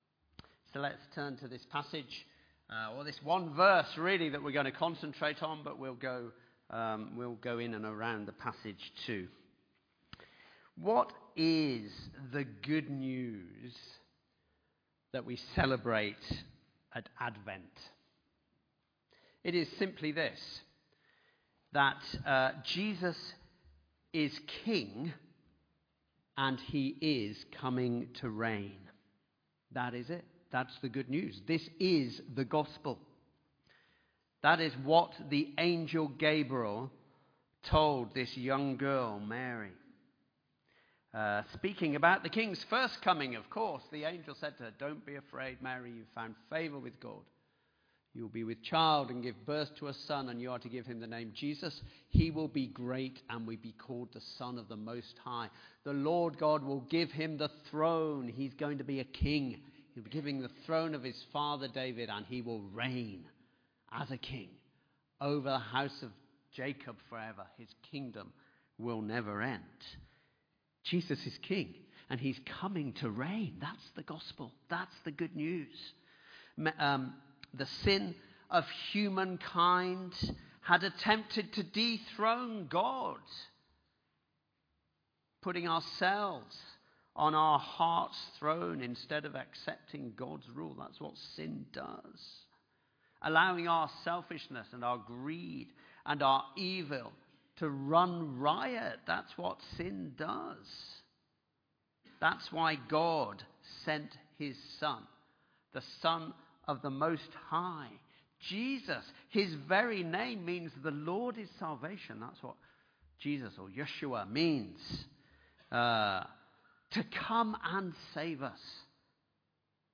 Sermon-7th-December-2025.mp3